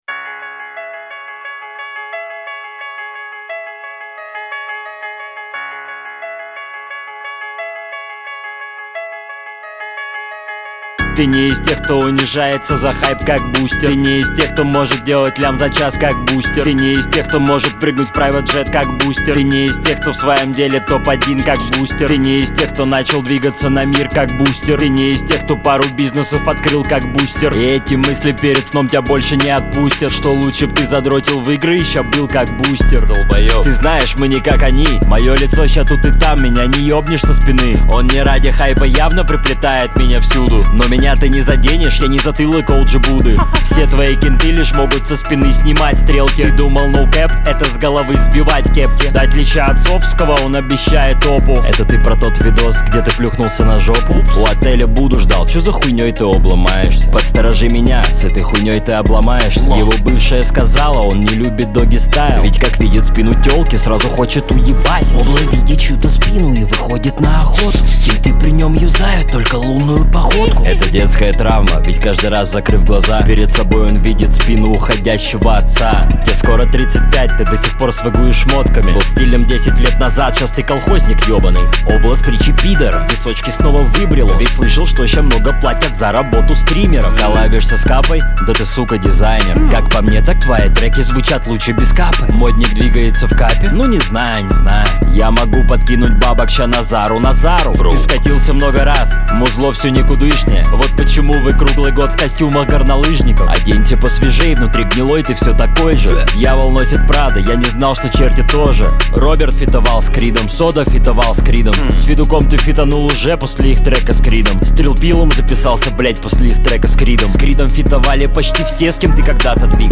дисс